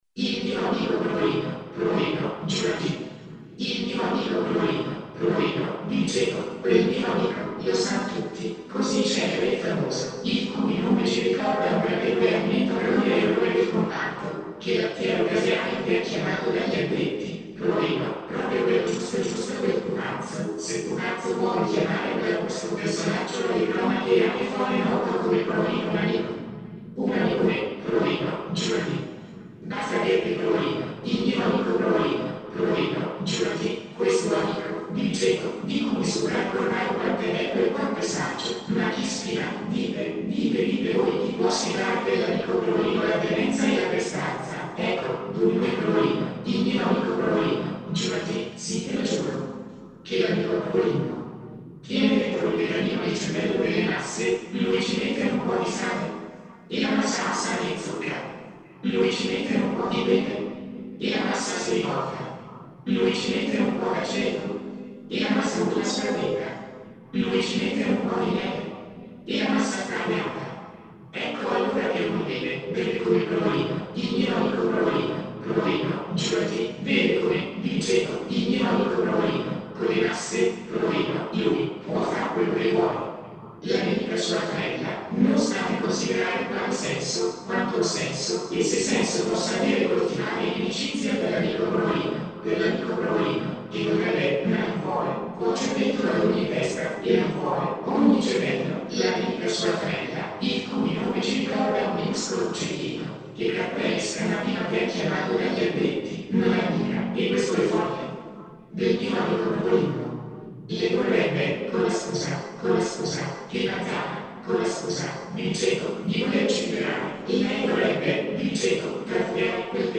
Click qui per ascoltare una declamazione automatica del testo che segue